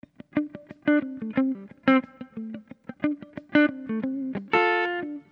Loops guitares rythmique- 100bpm 3
Guitare rythmique 59